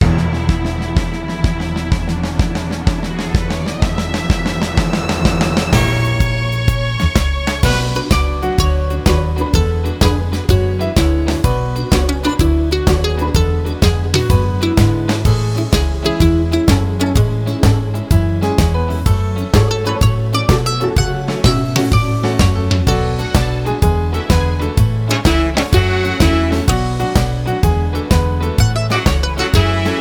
Inst